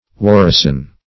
warison - definition of warison - synonyms, pronunciation, spelling from Free Dictionary
Warison \War"i*son\, n. [OF. warison safety, supplies, cure, F.